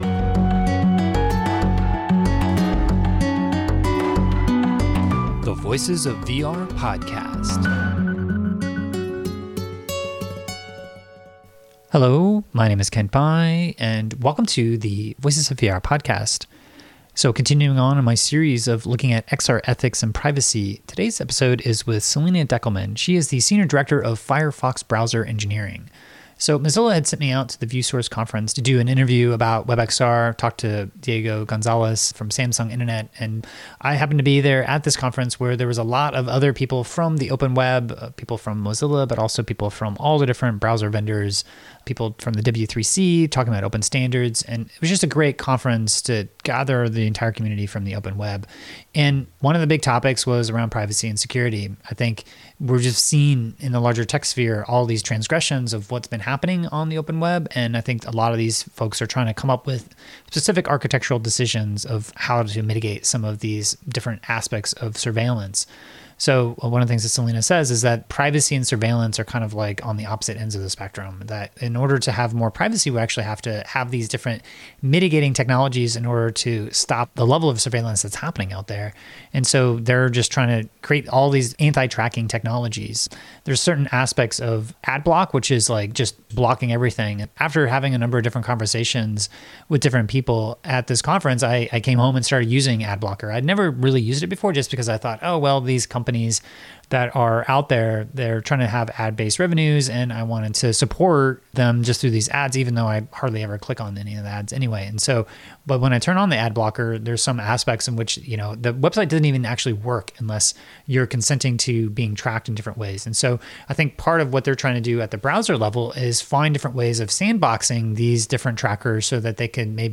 at the View Source Conference